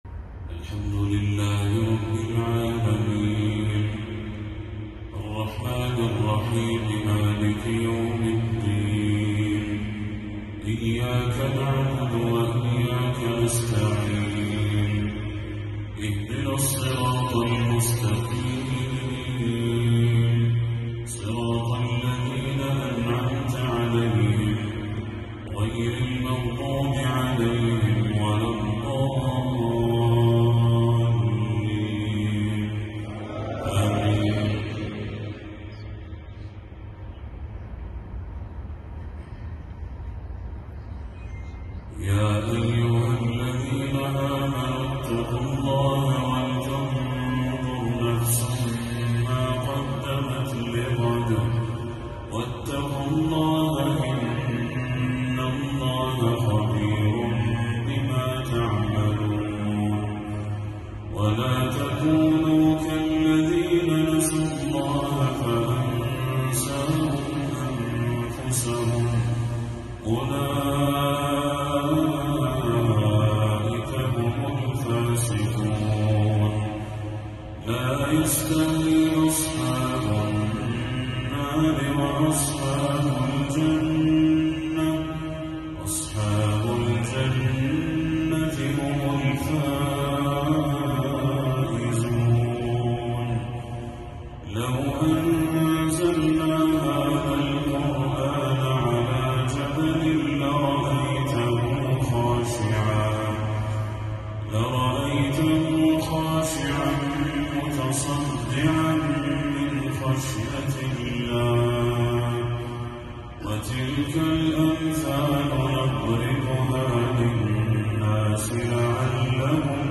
تلاوة ندية لخواتيم سورة الحشر للشيخ بدر التركي | مغرب 24 صفر 1446هـ > 1446هـ > تلاوات الشيخ بدر التركي > المزيد - تلاوات الحرمين